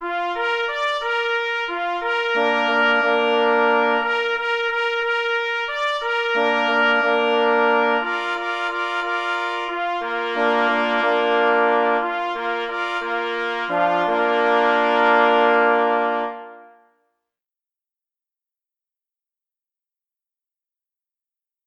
3 plesy i 2 parforsy